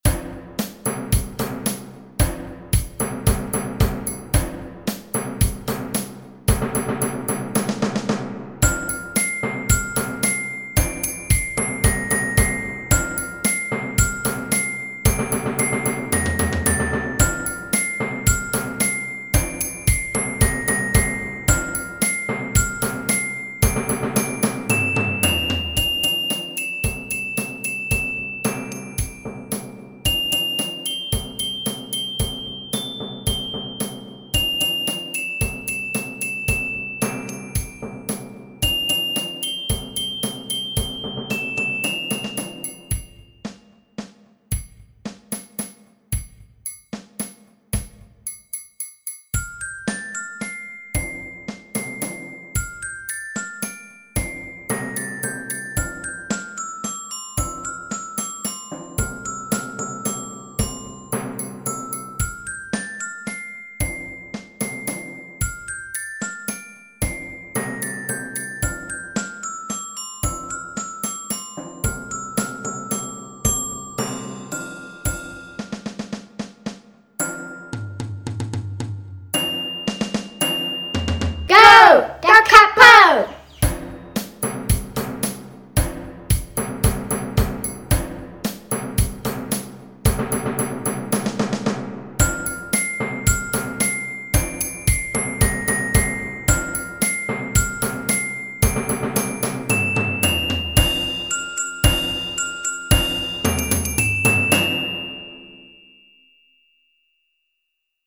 Gypsy music
Percussion ensemble